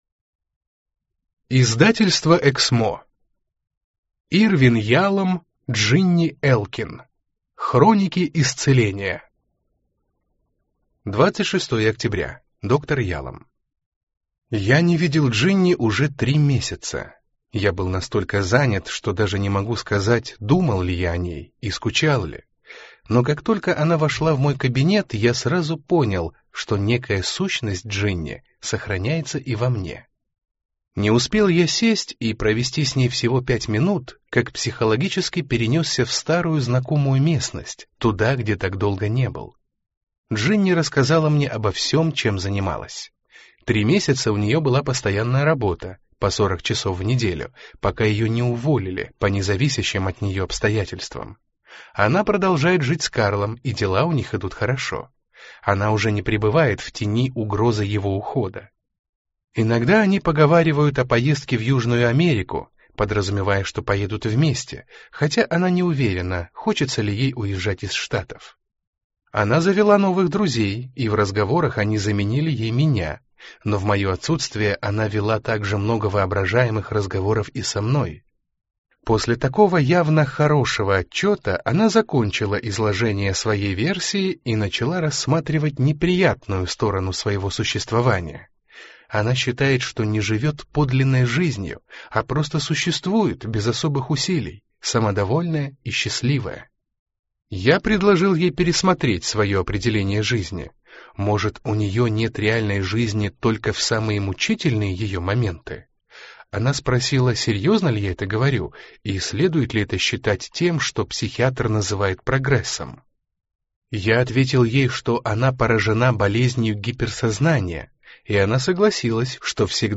Аудиокнига Хроники исцеления | Библиотека аудиокниг
Прослушать и бесплатно скачать фрагмент аудиокниги